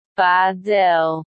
In Nederland spreken we het uit als “pa del” in twee lettergrepen met de klemtoon op de eerste lettergreep.
Hier onder kan je luisteren naar de correcte uitspraak van het woord Padel:
pa-del.mp3